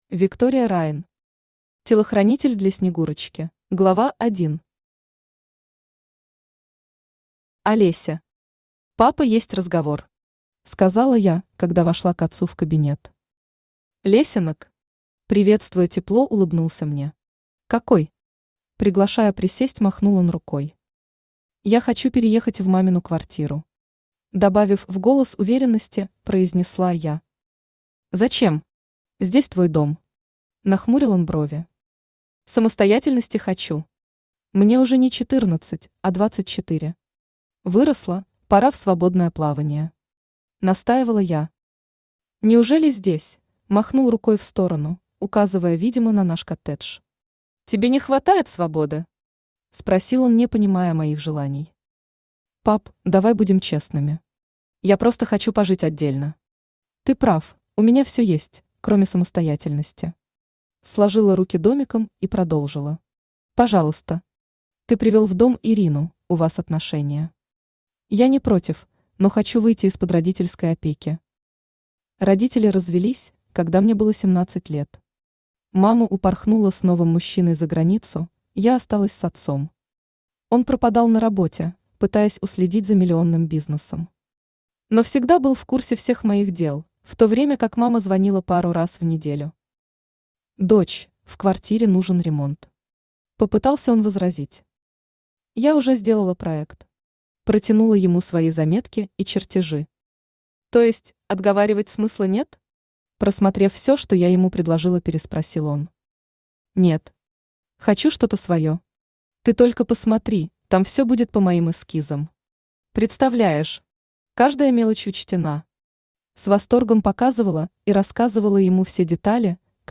Aудиокнига Телохранитель для снегурочки Автор Виктория Райн Читает аудиокнигу Авточтец ЛитРес.